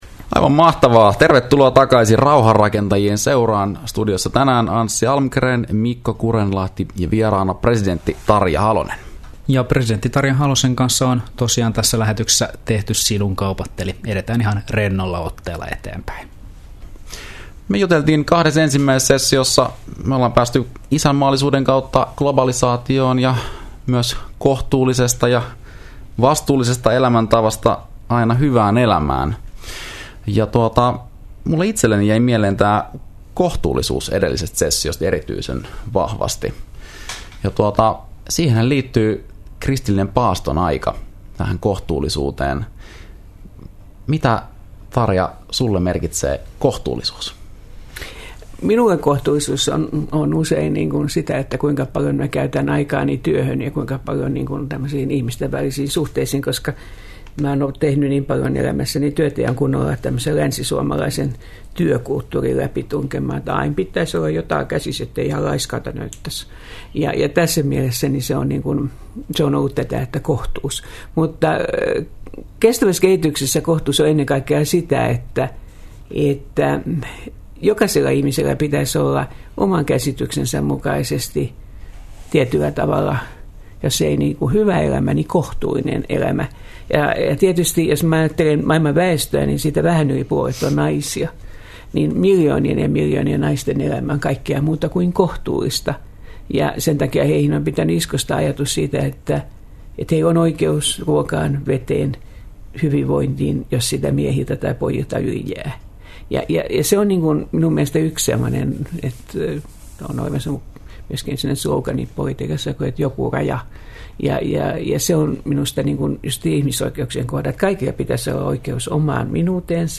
Radio Dein Rauhanrakentajat -ohjelmassa toimittajina torstaisin klo 16 ja uusintana sunnuntaisin klo 16.